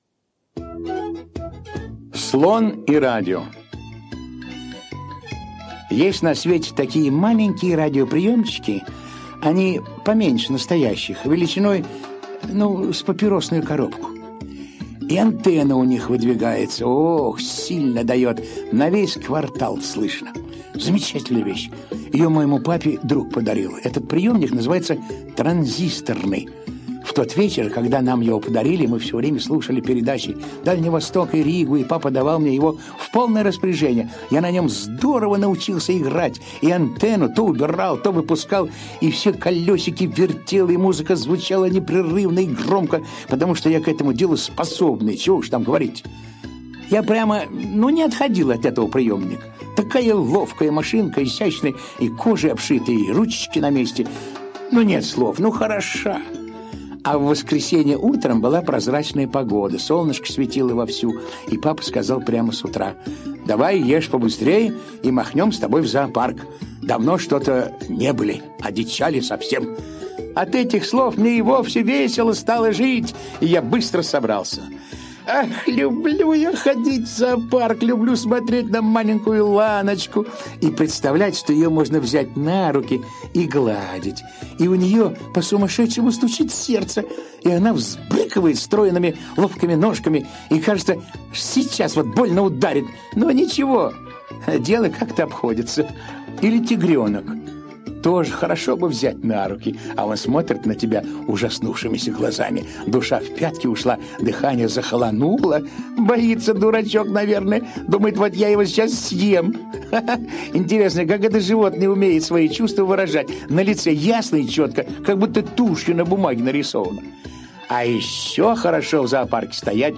Слон и радио - аудио рассказ Драгунского В.Ю. Дениска с папой пришли в зоопарк.